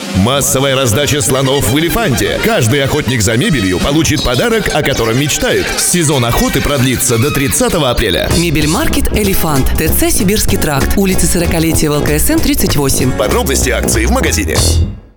Ролик для радио, мебельмаркет "Элефант", г. Екатеринбург Категория: Копирайтинг